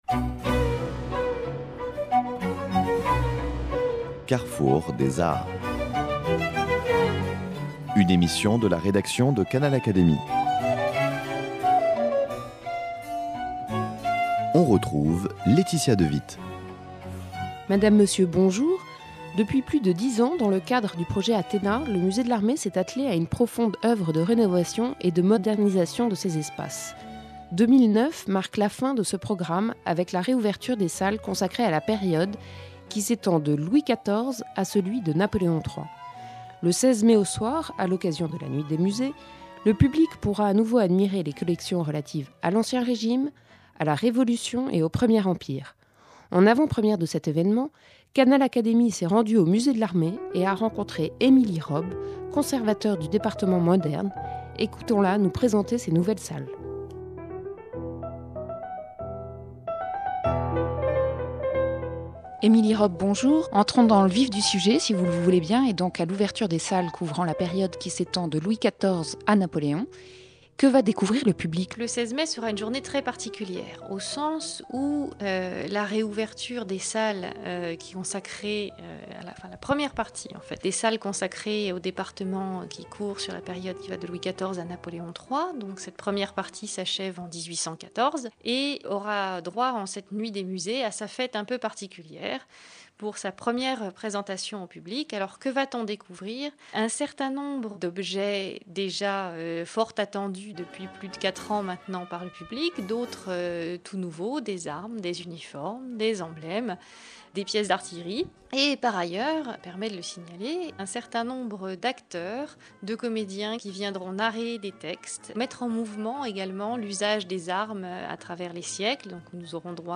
Canal Académie s’est rendu au musée de l’Armée